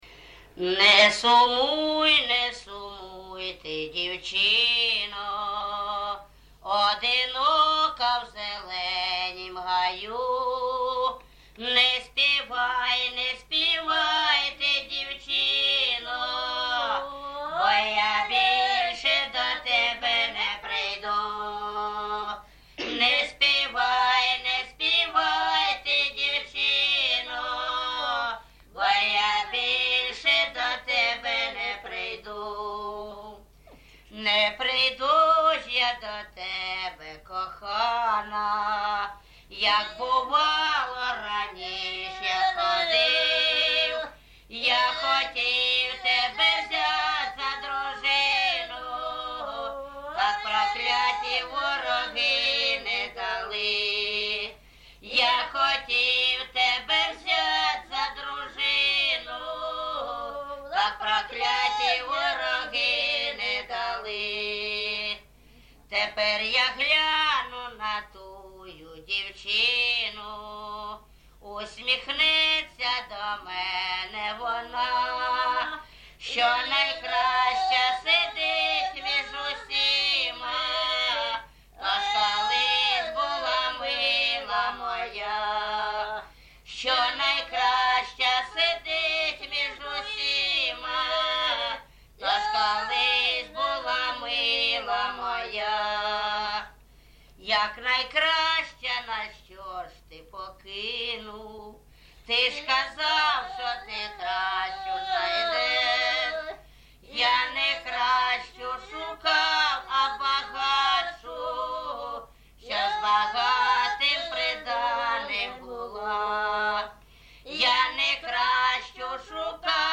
ЖанрСучасні пісні та новотвори
Місце записус. Гарбузівка, Сумський район, Сумська обл., Україна, Слобожанщина